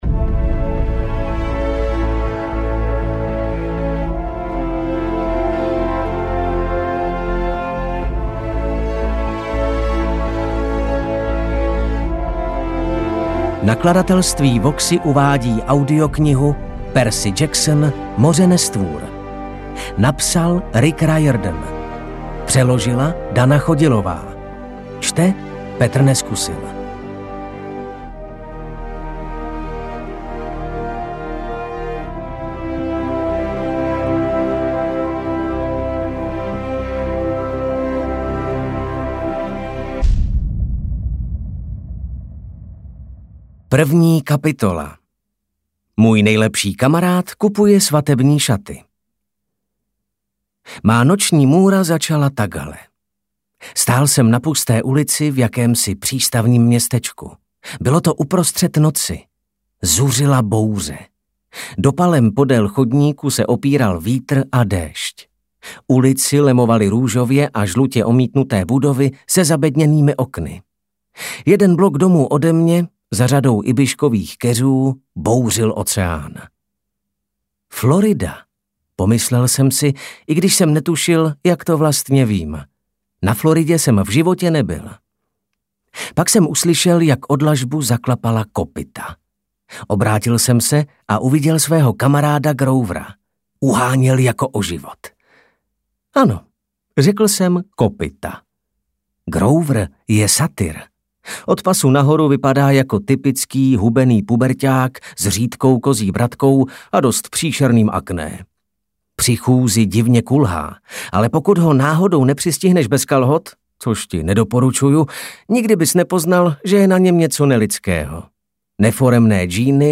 Neuvěřitelné se stalo skutečností a je tu audioknižní pokračování Percyho Jacksona.